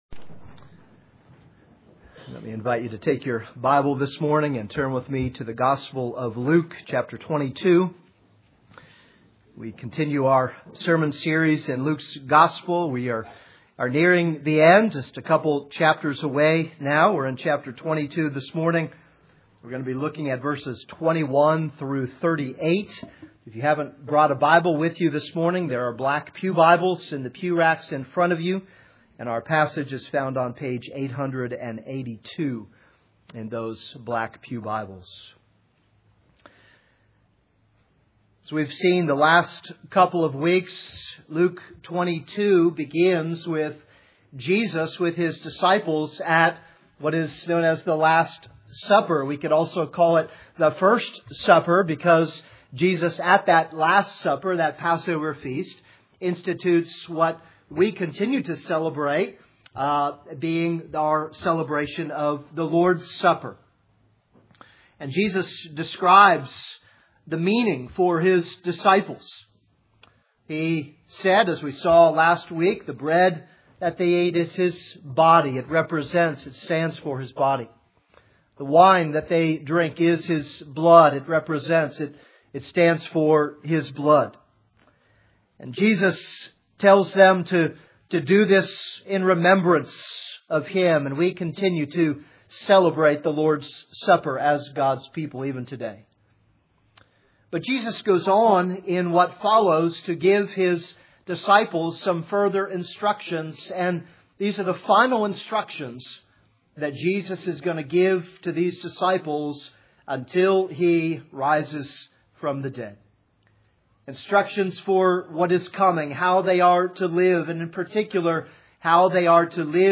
This is a sermon on Luke 22:21-38.